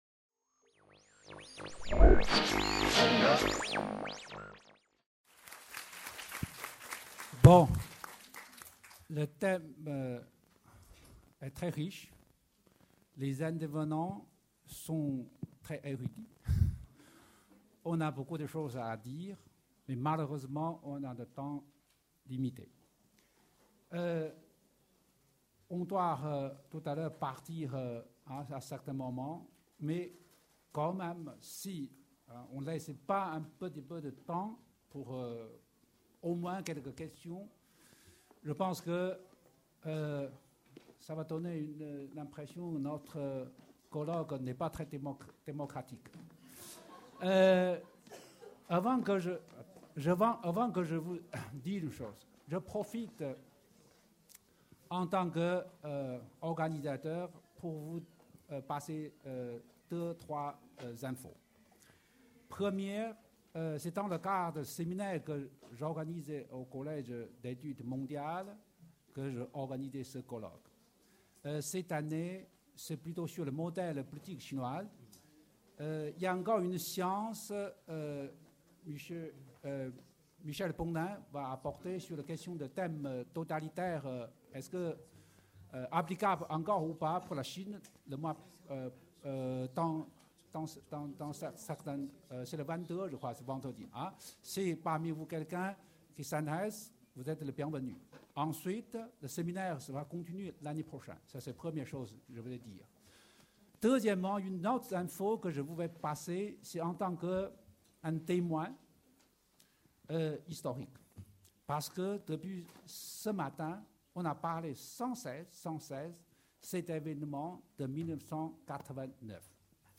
Table ronde : questions et discussions (Partie 7) | Canal U